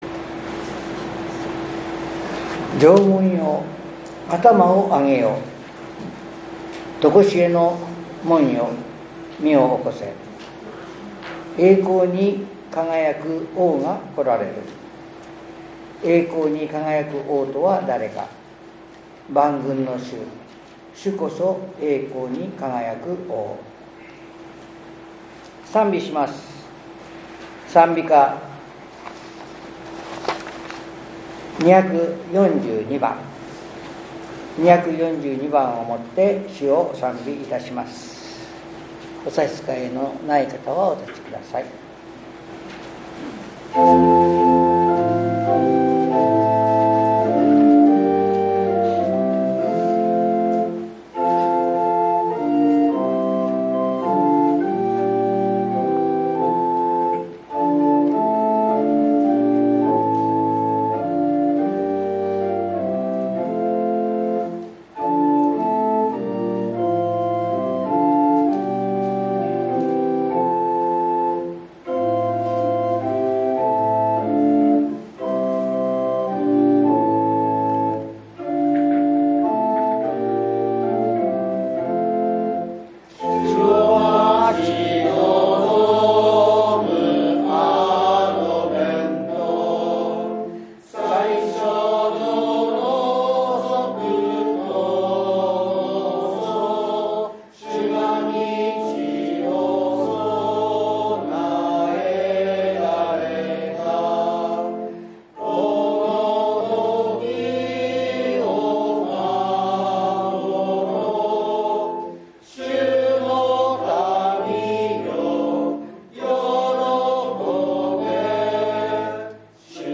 １２月２９日（日）主日礼拝